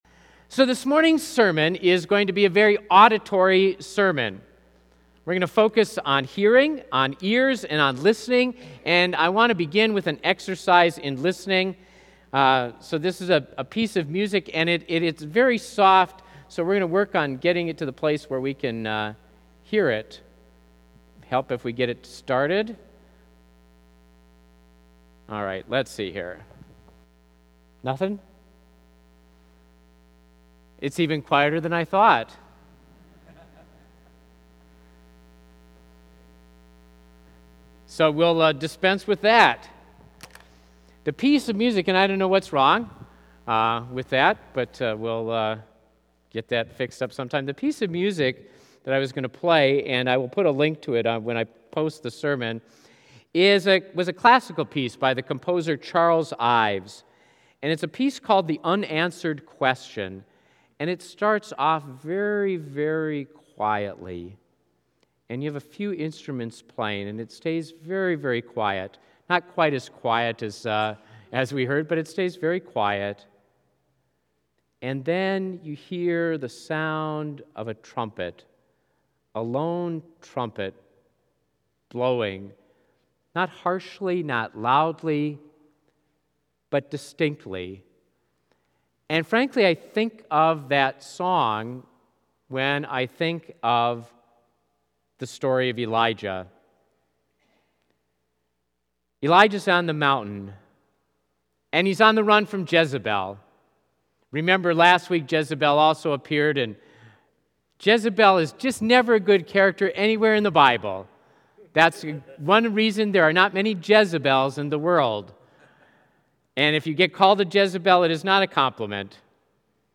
This is going to be a very auditory sermon.